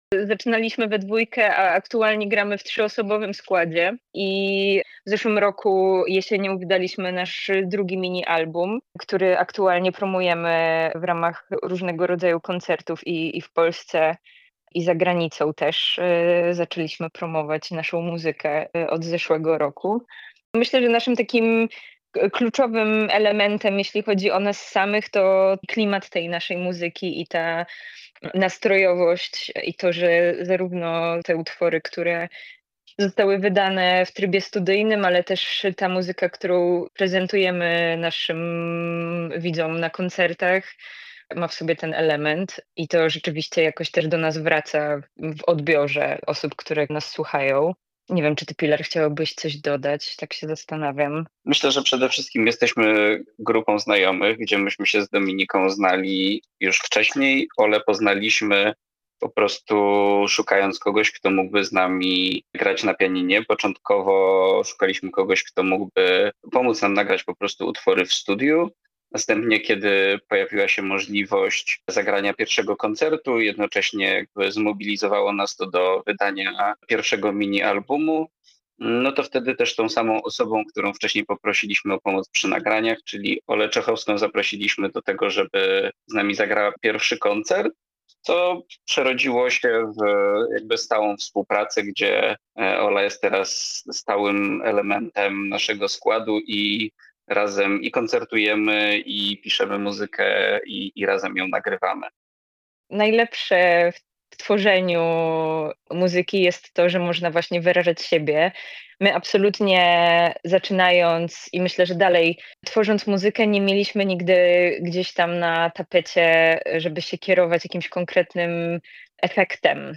MUD/O [mu-do] to warszawskie trio poruszające się na pograniczu alternatywy, indie-folku i dreampopu.
Ich brzmienie koncentruje się na charakterystycznych wokalach w otoczeniu ciepłego pianina i bębnach o pulsującym rytmie.